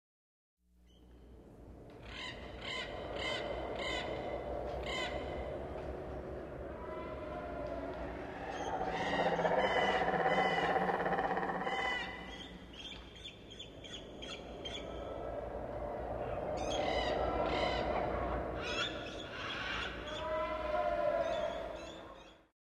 Жуткая ночная атмосфера с карканьем ворон и завывающим ветром